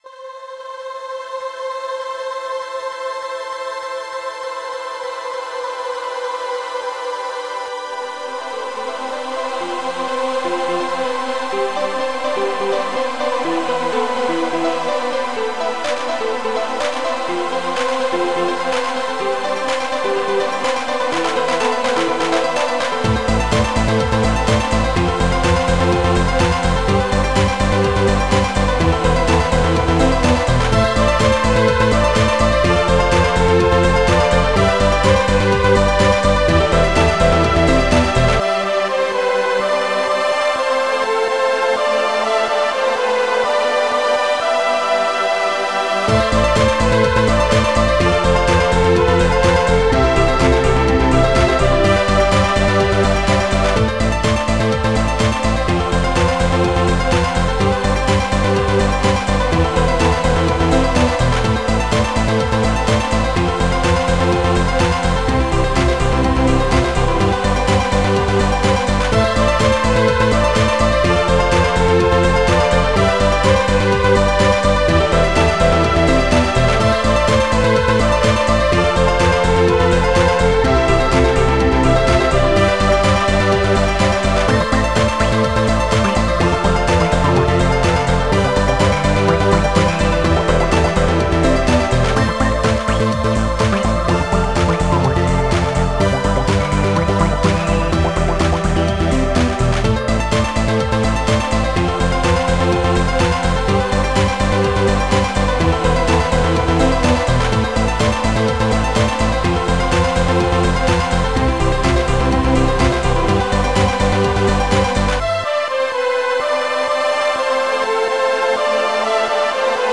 Extended Module